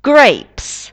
grapes [greips]